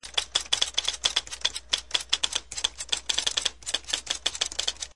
打字机打字5秒
描述：这是一个佳能电动打字机的五秒音频，用一台距打字机大约12英寸的简单电脑麦克风录制